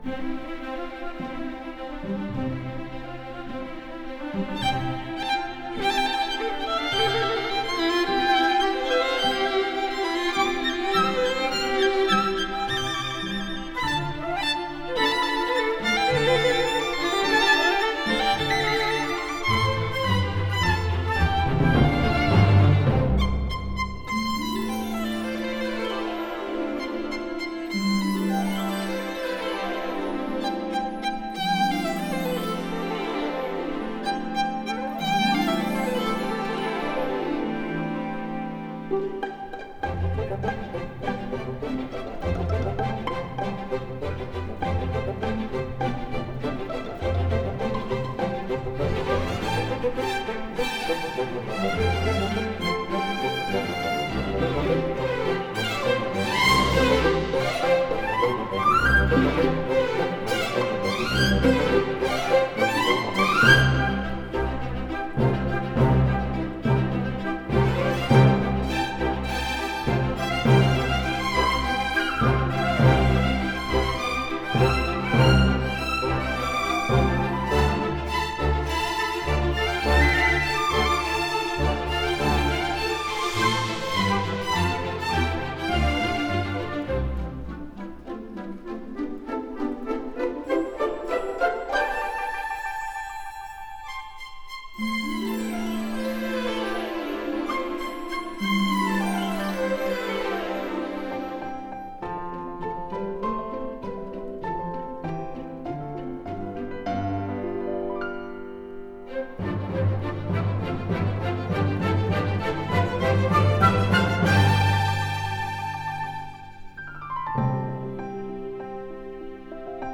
Entre musique classique et sonorités plus modernes
Une musique élégante et enjouée